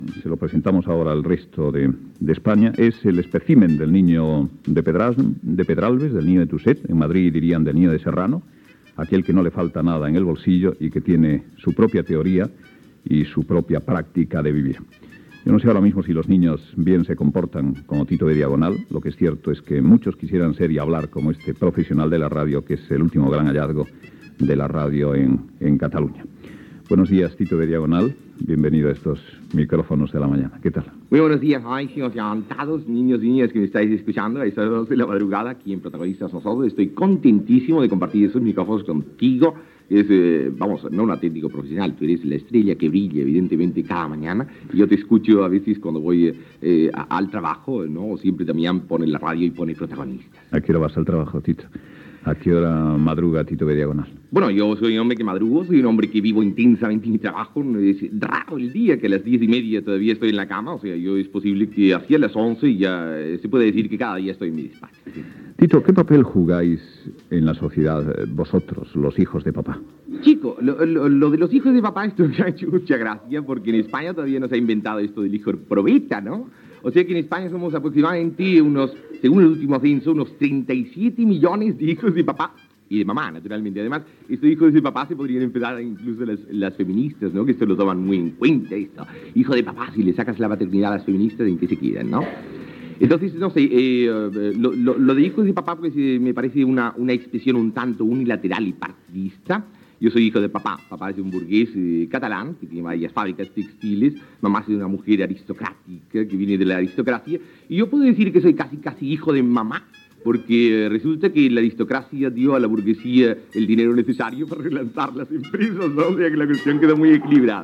Gènere radiofònic Info-entreteniment